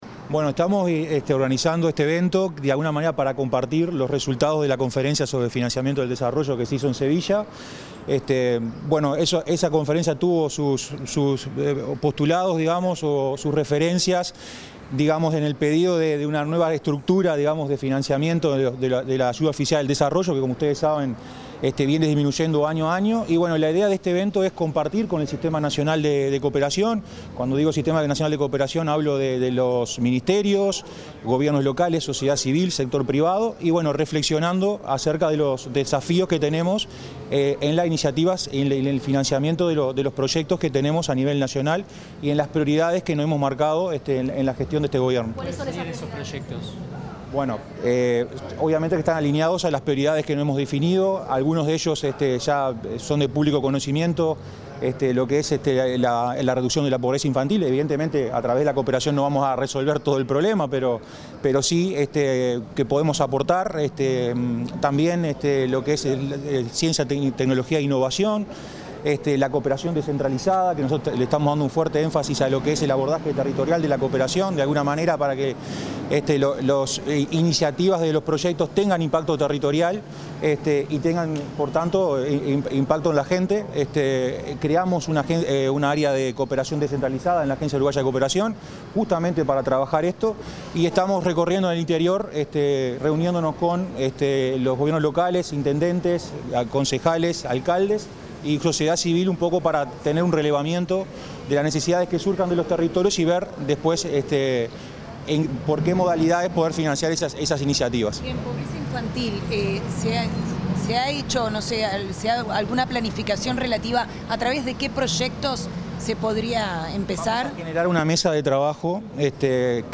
Declaraciones del director ejecutivo de la AUCI, Martín Clavijo
Declaraciones del director ejecutivo de la AUCI, Martín Clavijo 28/08/2025 Compartir Facebook X Copiar enlace WhatsApp LinkedIn El director ejecutivo de la Agencia Uruguaya de Cooperación Internacional (AUCI), Martín Clavijo, realizó declaraciones en el marco de la apertura del evento Del Compromiso de Sevilla a la Acción.